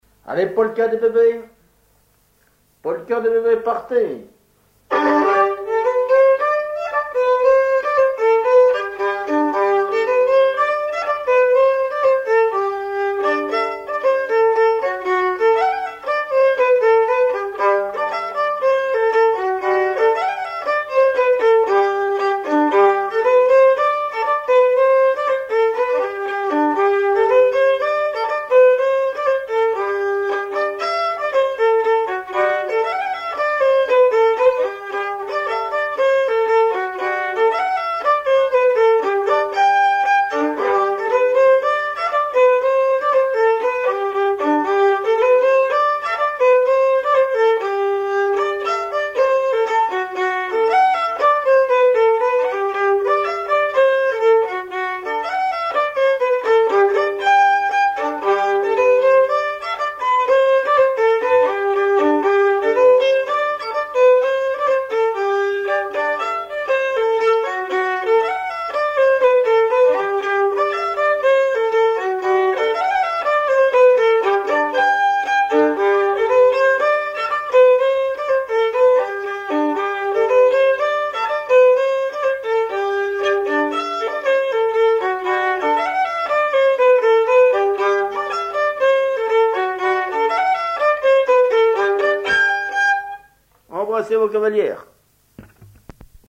danse : polka des bébés ou badoise
Auto-enregistrement
Pièce musicale inédite